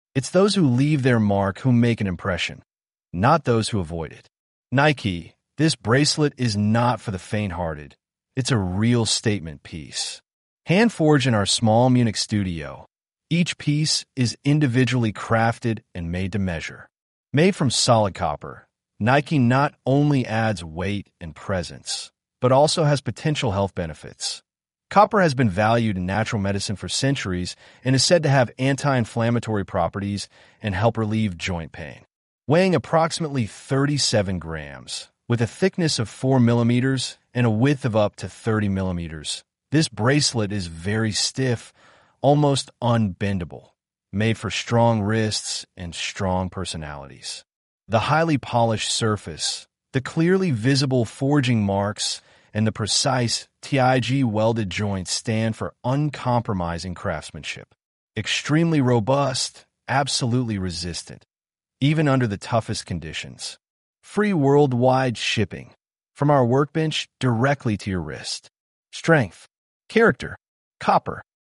Nike2-engl-rttsreader.mp3